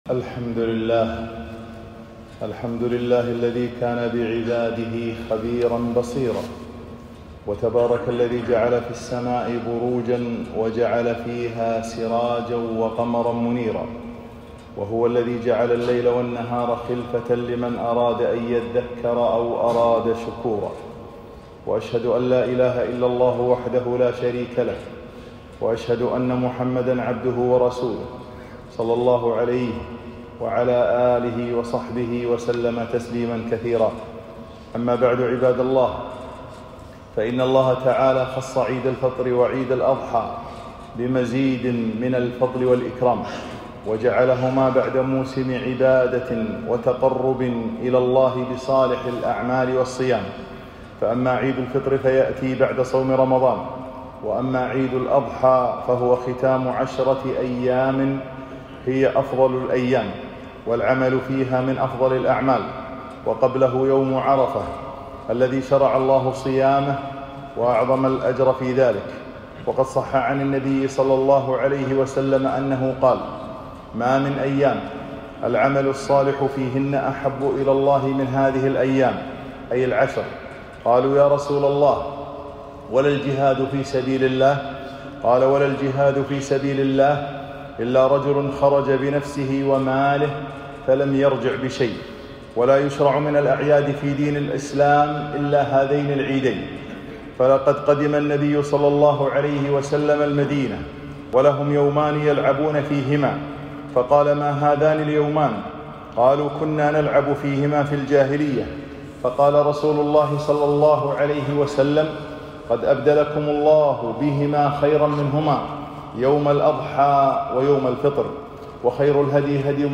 خطبة - من أحكام العيدين - الجمعة ٩ ذي الحجة ١٤٤٣ هـ